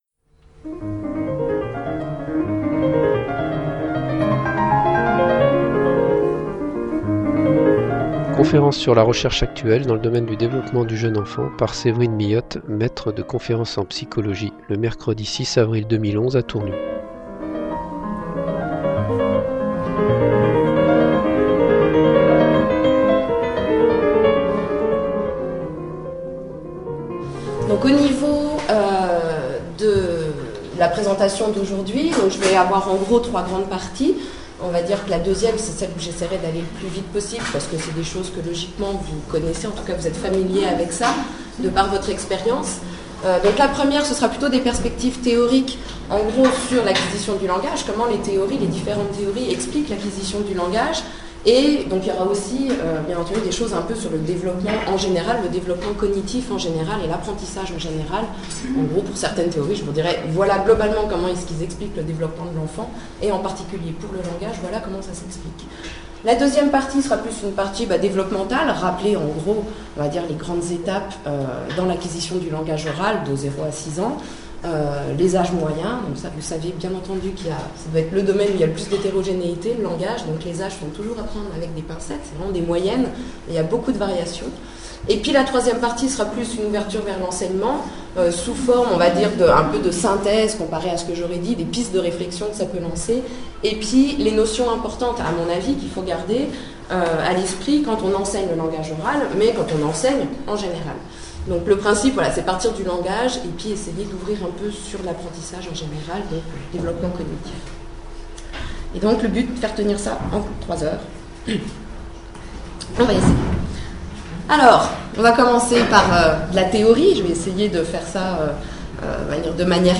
Cette conférence s’est tenue le 6 avril 2011 à Tournus et a été enregistrée.